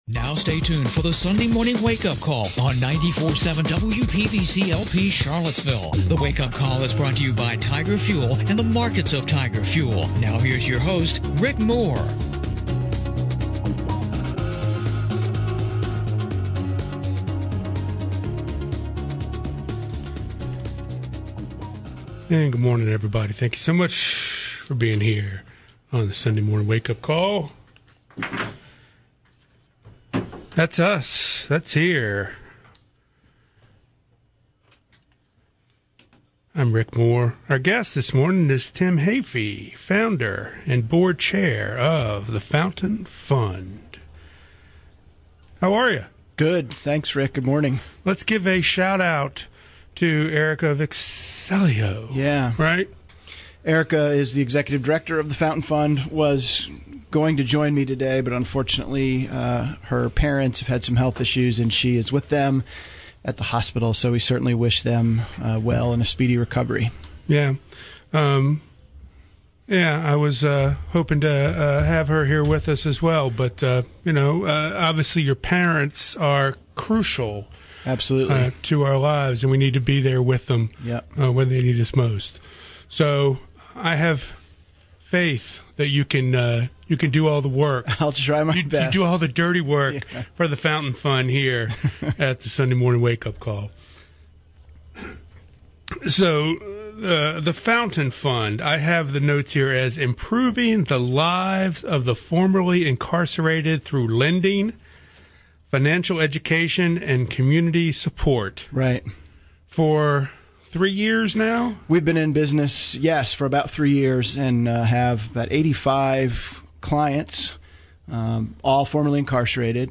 talks with Tim Heaphy from The Fountain Fund about their efforts to improve the lives of the formerly incarcerated. Topics include: Examining the hurdles that follow the release from prison and recent legislative changes affecting the suspension of driver’s licences for the non-payment of court fees.